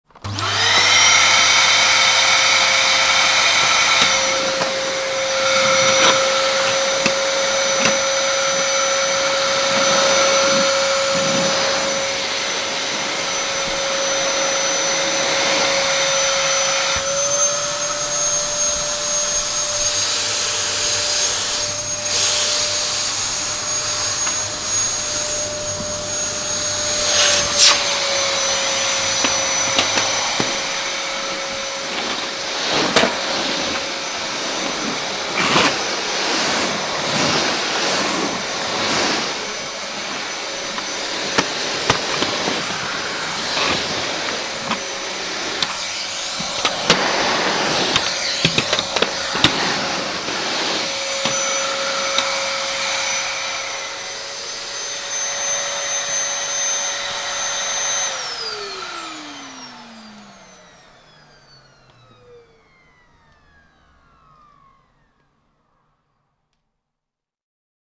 Пылесос для дома